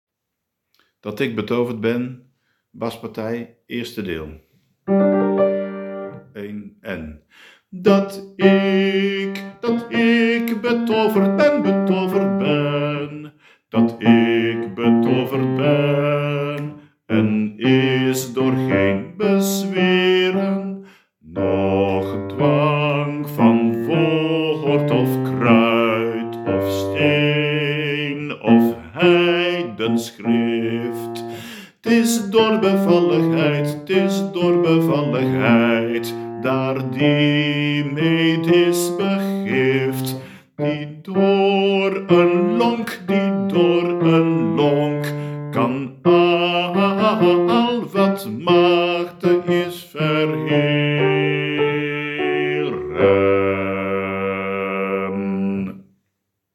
Gemengd koor uit Wijk bij Duurstede.
bas deel 1
Dat_ick_betovert_dl_1_bas.m4a